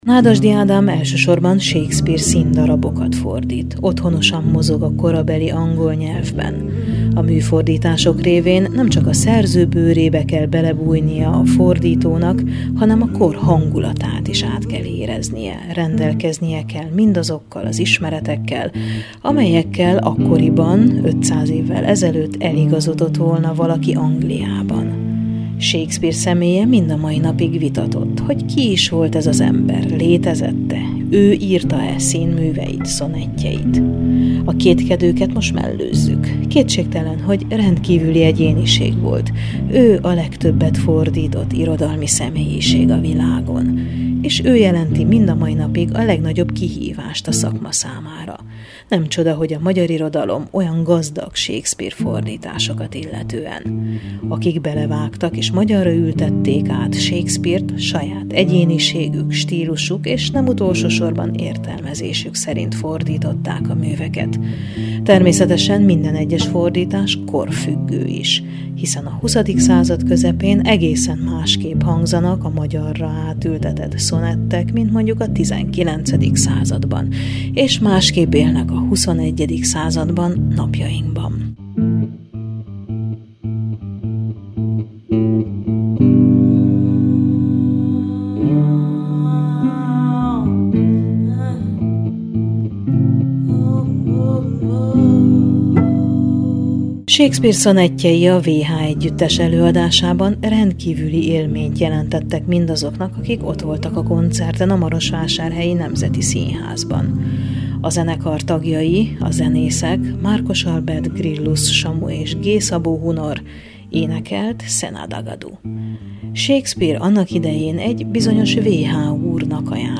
A 23. Marosvásárhelyi Nemzetközi Könyvvásár egyik jeles meghívottja volt Nádasdy Ádám nyelvész, költő és műfordító. “Kettős minőségében” volt jelen az eseményen, a nyelv alakulásáról, a shakespeare-i nyelvről, fordításokról beszélgetett vele Kovács András Ferenc, majd költeményeiből is felolvasott.